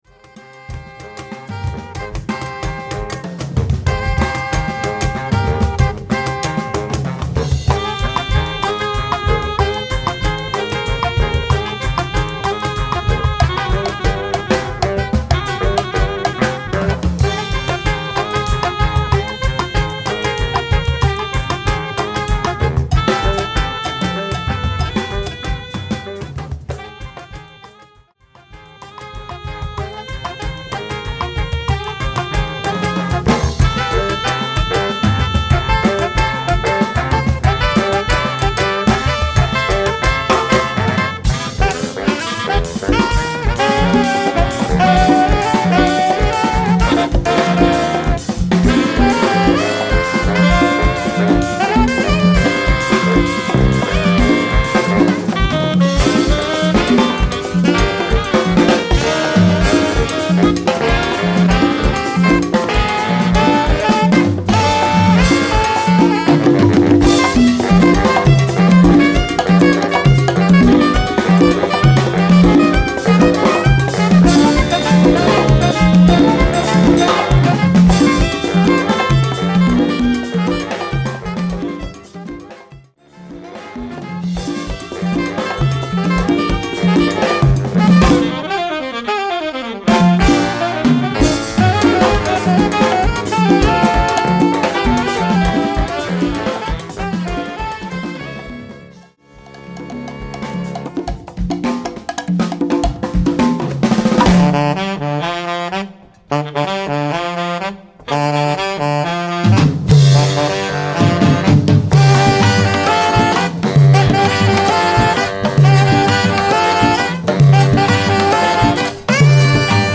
Category: combo (sextet)
Style: bugalu/afro-cuban
Solos: tenor sax
Instrumentation: alto 1-2, tenor, bari, clave, drumset